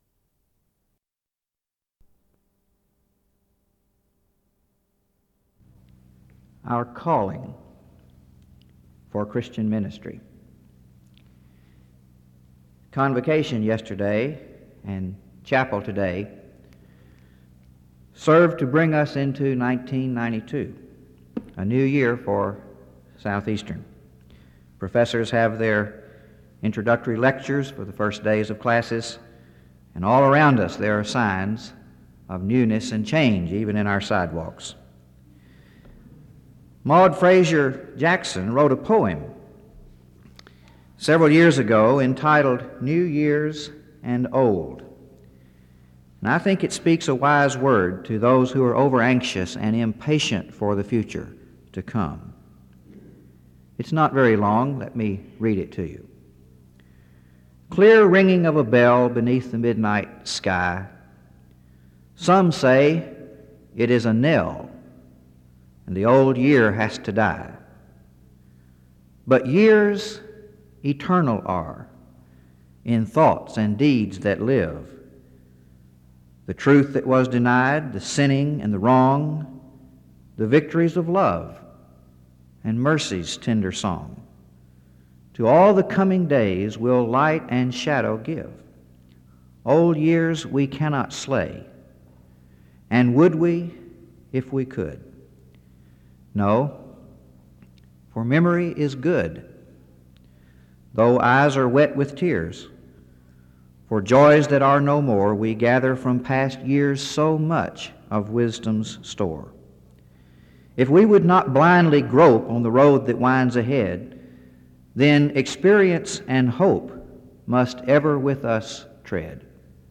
The audio was transferred from audio cassette.
Location Wake Forest (N.C.)